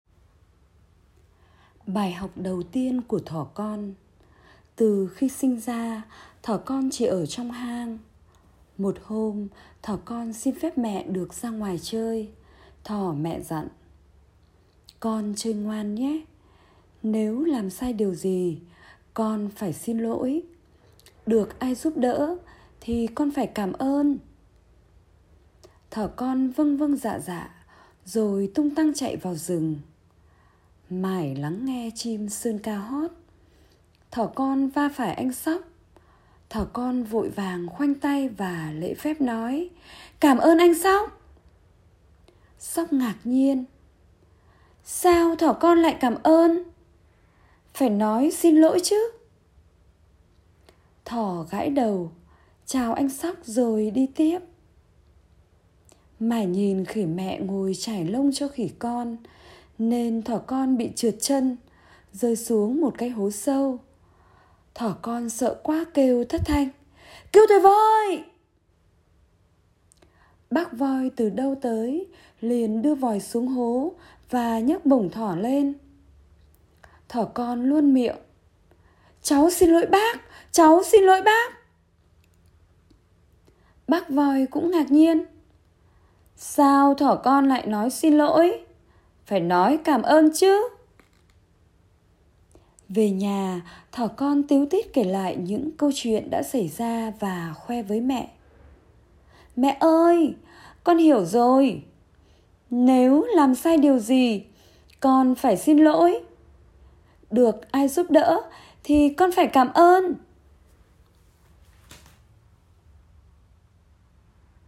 Sách nói | BÀI HỌC ĐẦU TIÊN CỦA THỎ - TẬP ĐỌC LỚP 1